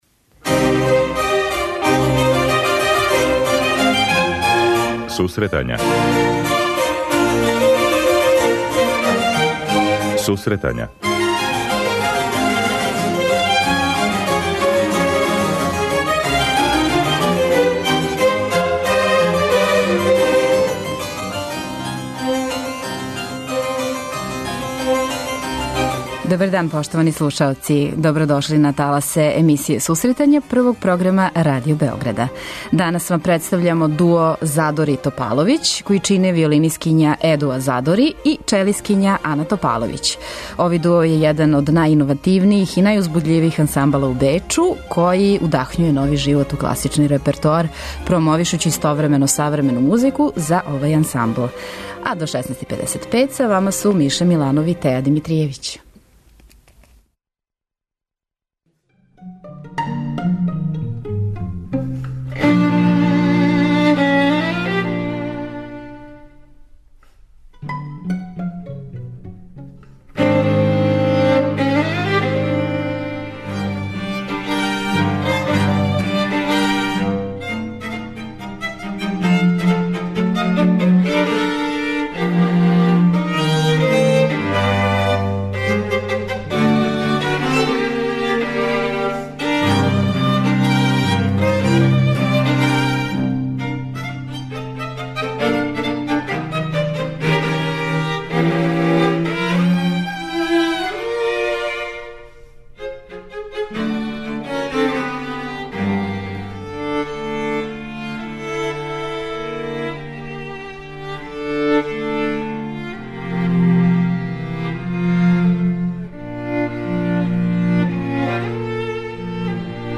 виолинистикња
виолончелисткиња